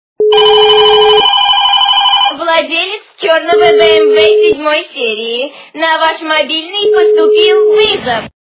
При прослушивании Звонок владельцу БМВ - На ваш мобильный поступил вызов качество понижено и присутствуют гудки.